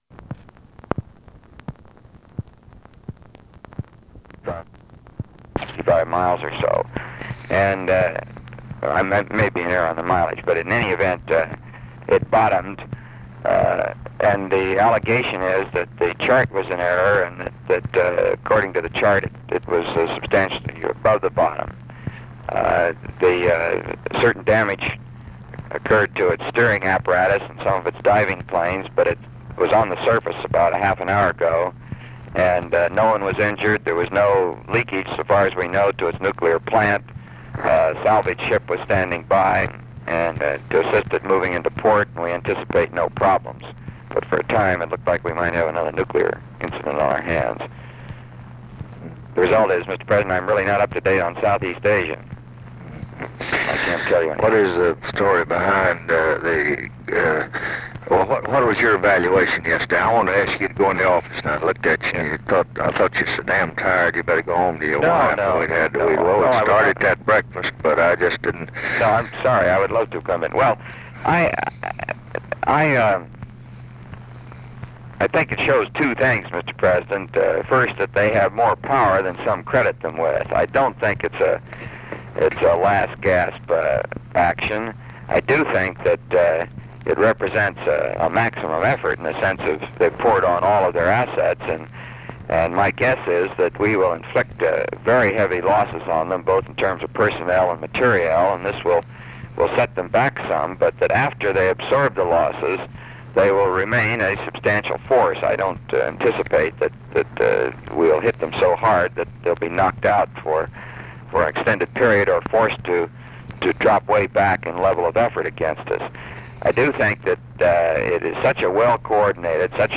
Conversation with ROBERT MCNAMARA
Secret White House Tapes